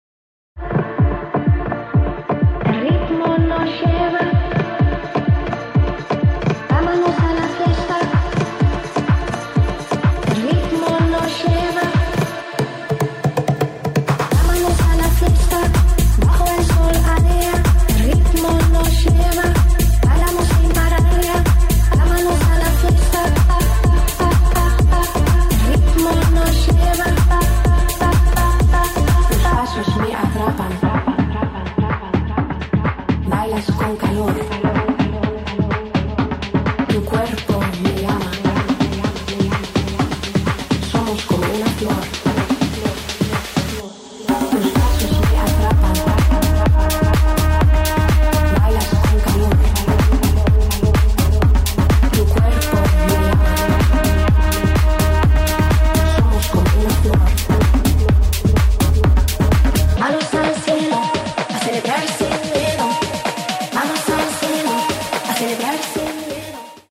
拉丁科技屋——感受节奏，掌握律动！
这里融合了拉丁美洲的节奏和现代科技的能量。
音色展示